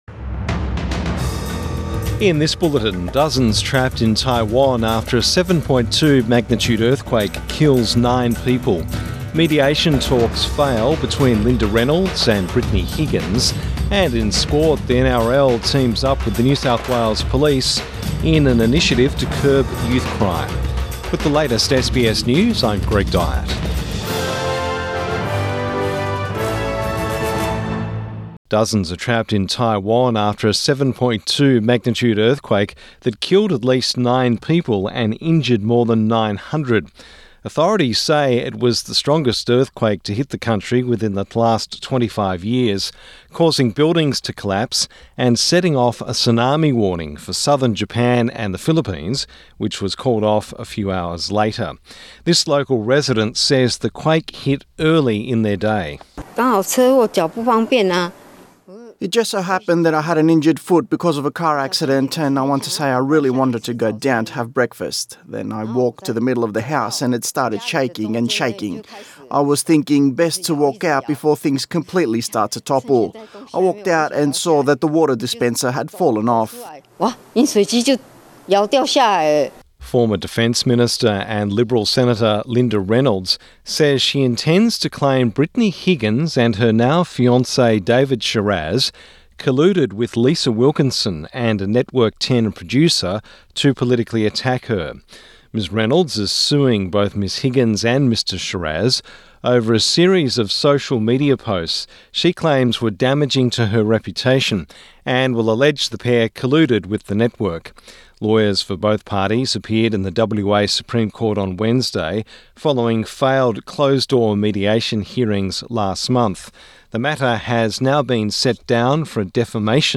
Morning News Bulletin 4 April 2024